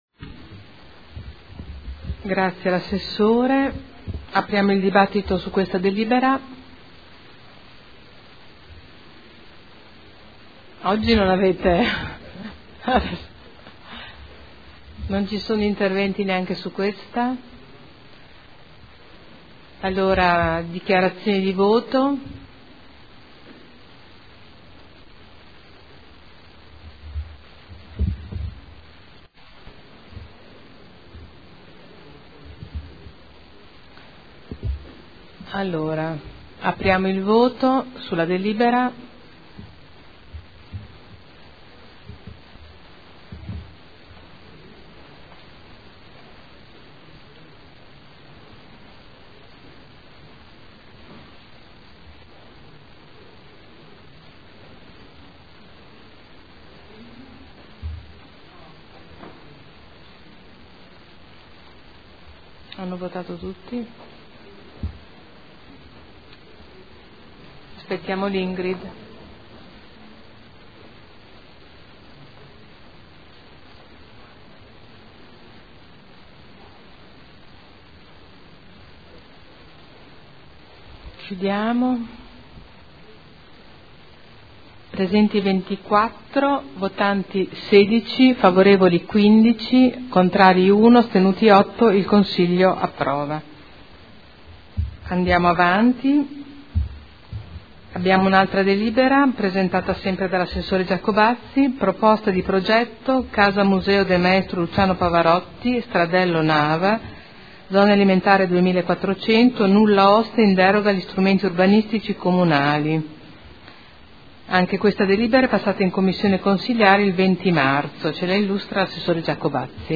Seduta del 31 marzo. Proposta di deliberazione: Adeguamento alla L.R. n. 15/2013 – Variante al Regolamento Urbanistico Edilizio (RUE) – Approvazione.